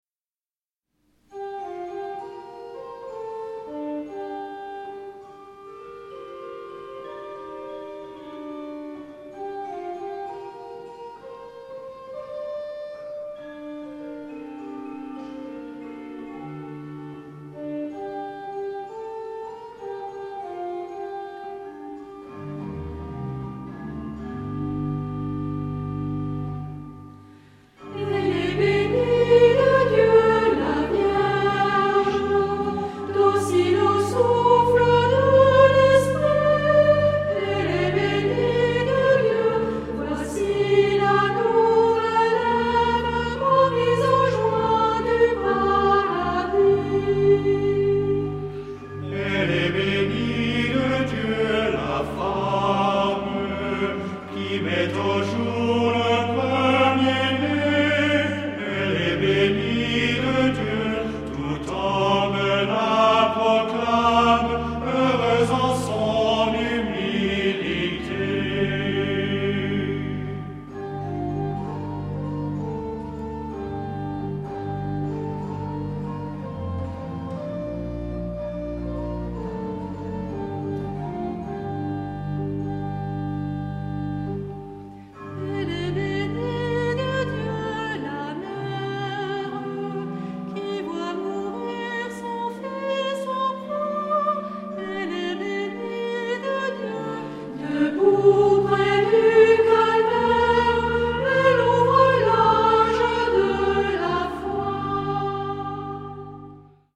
Genre-Style-Forme : Hymne (sacré)
Caractère de la pièce : recueilli ; calme ; lent
Type de choeur :  (1 voix unisson )
Instrumentation : Orgue  (1 partie(s) instrumentale(s))
Tonalité : sol mineur